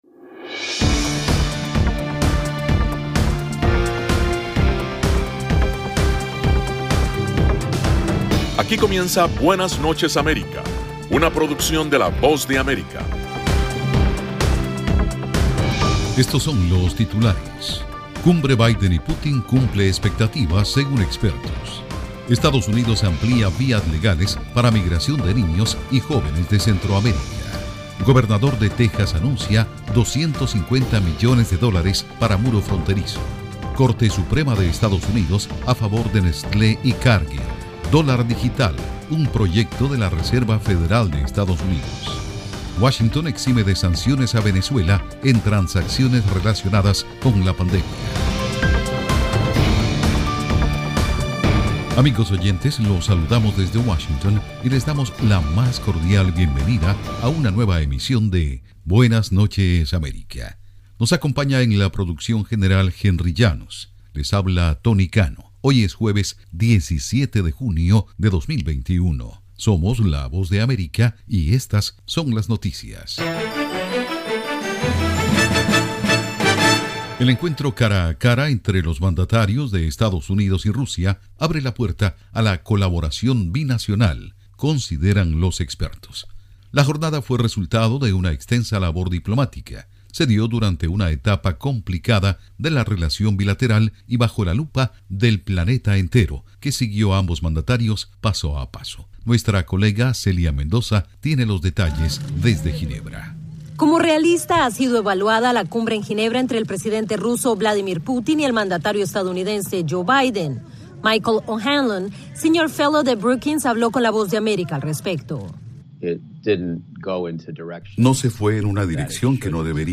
PROGRAMA INFORMATIVO DE LA VOZ DE AMERICA, BUENAS NOCHES AMERICA.